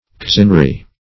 Cousinry \Cous"in*ry\ (k?z"'n-r?), n.